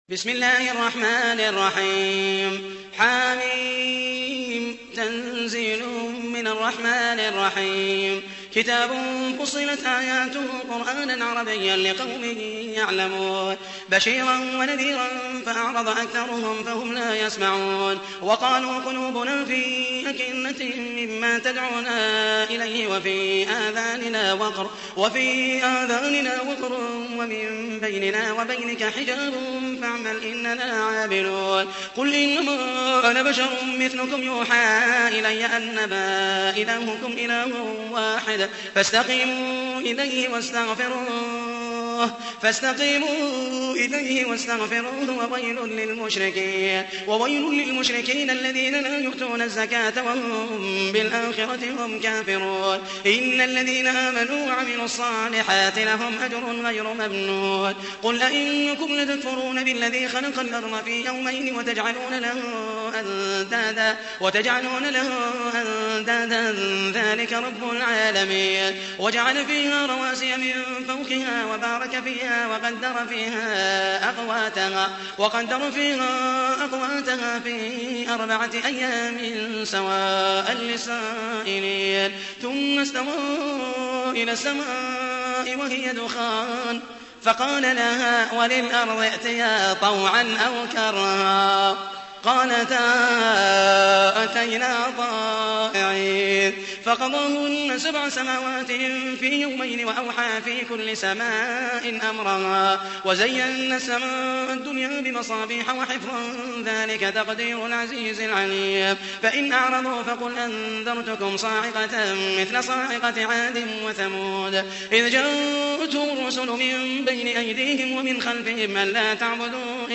تحميل : 41. سورة فصلت / القارئ محمد المحيسني / القرآن الكريم / موقع يا حسين